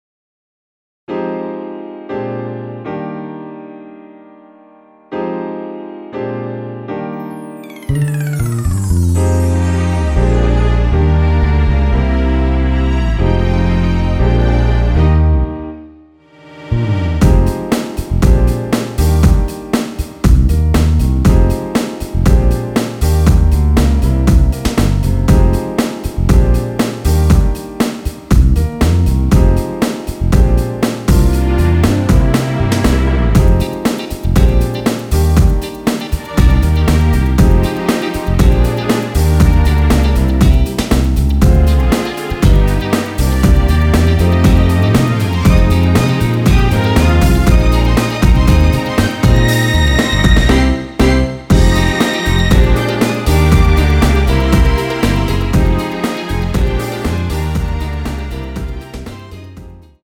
원키에서(+1)올린 MR입니다.
Eb
앞부분30초, 뒷부분30초씩 편집해서 올려 드리고 있습니다.